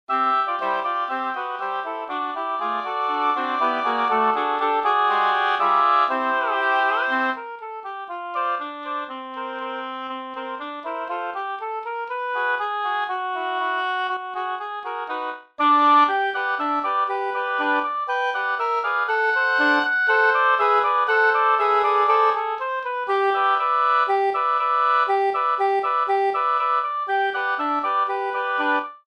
Formación: 4 oboes y 1 corno inglés
Ensemble Oboes
quinteto oboes